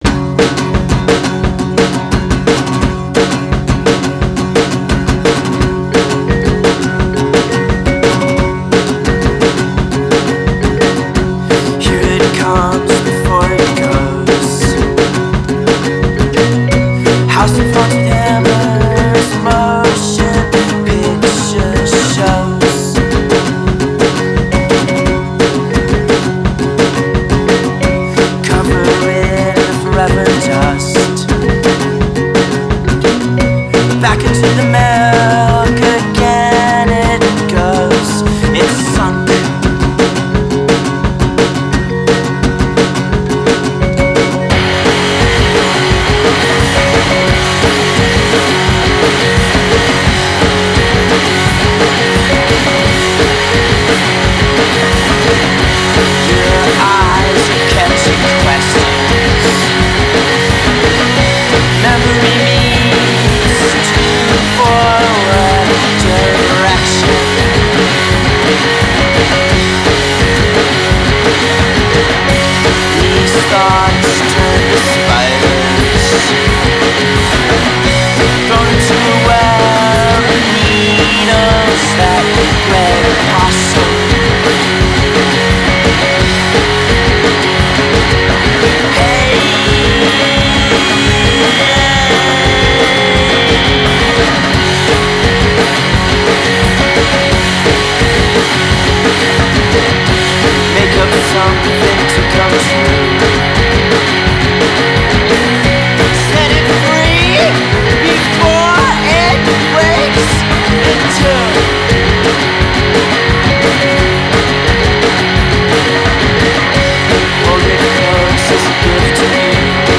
Vocals, Guitars
Drums, Keyboards
for all the noise it’s melodic